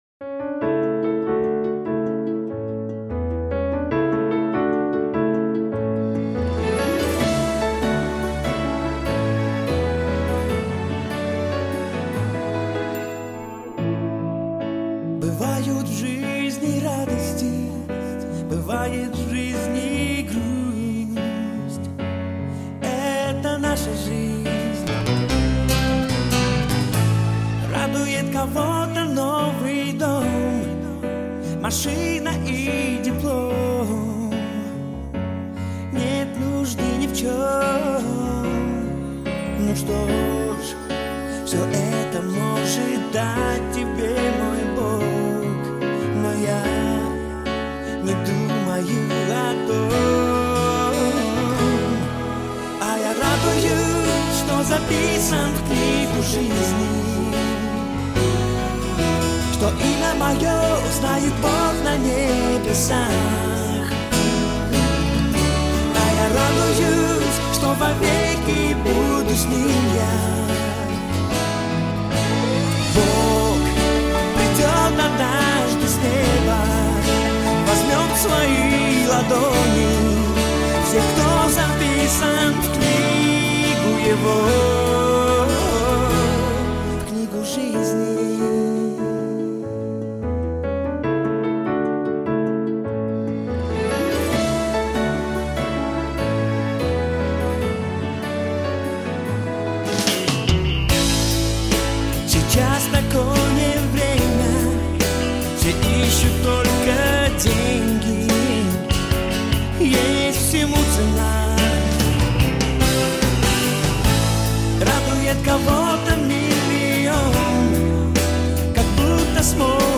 7580 просмотров 4543 прослушивания 663 скачивания BPM: 146